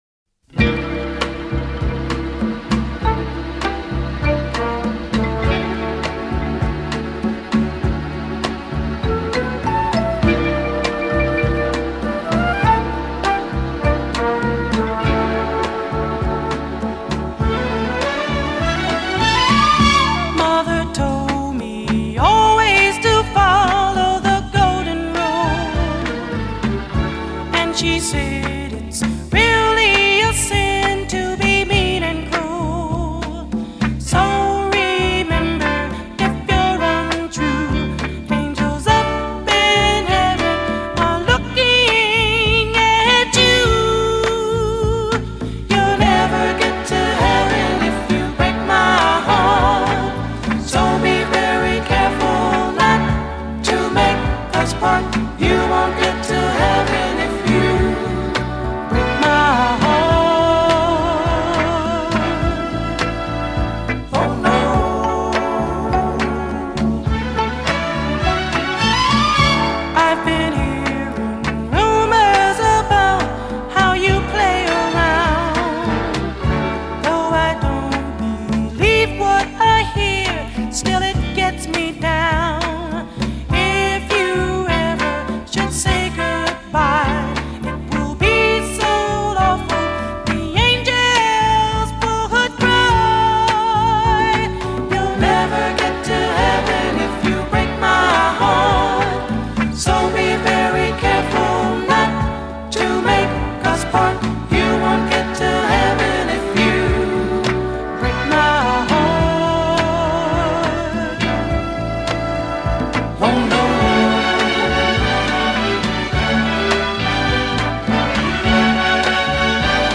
amazing falsetto